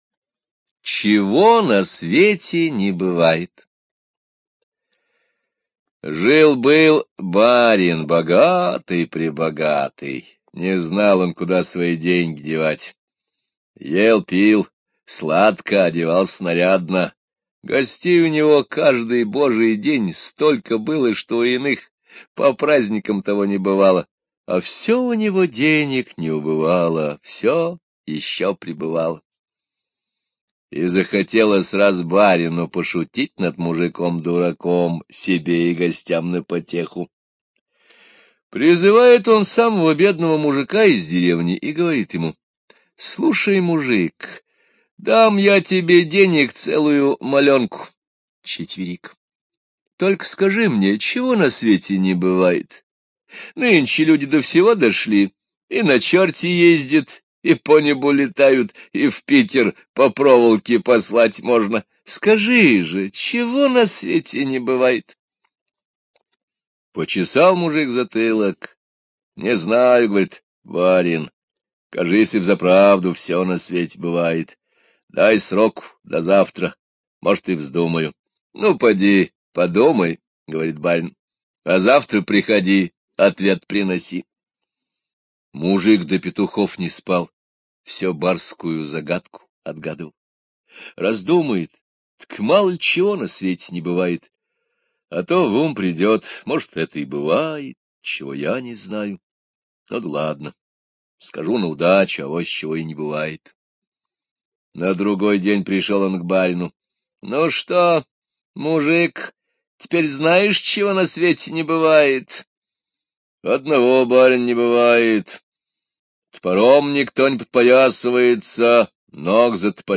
Русские народные аудиосказки